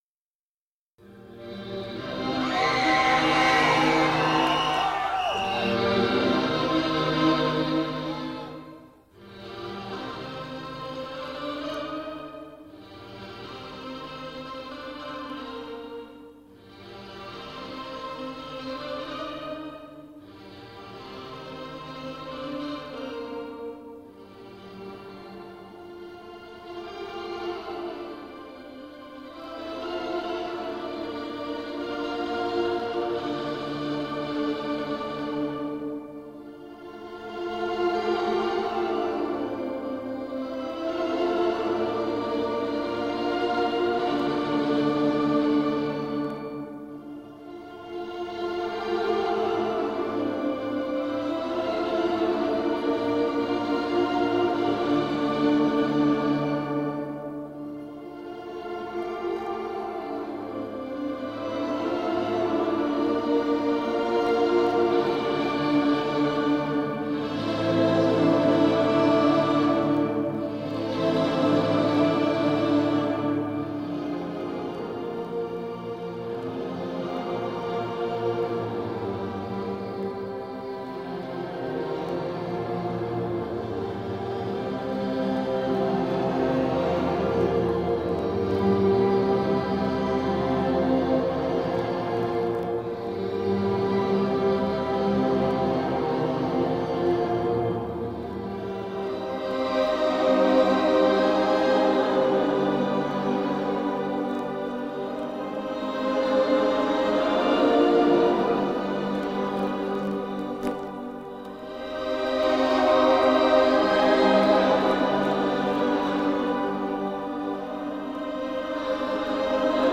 vocals
guitar
bass
drums
Goth of the intense variety.